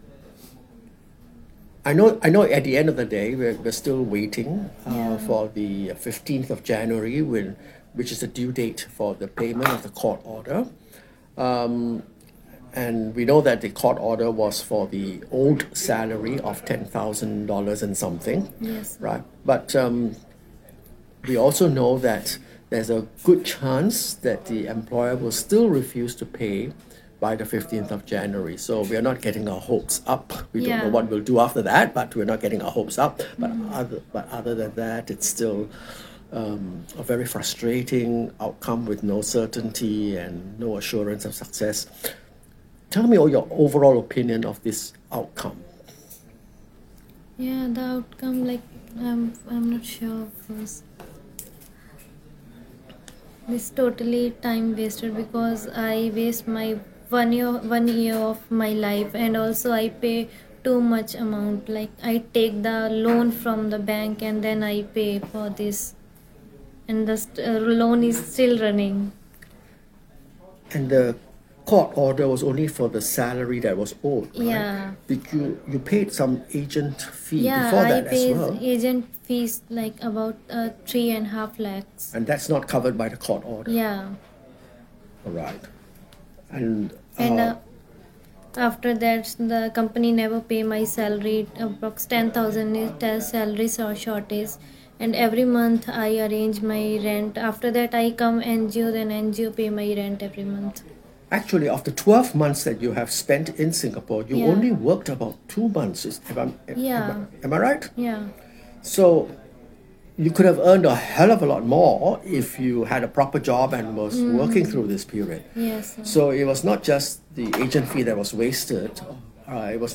TWC2 bought her a flight ticket – she had no money, and with TEPs, the employer is not responsible for repatriation – and we sat with her one more time, a week before the payment deadline of 15 January, for an exit interview.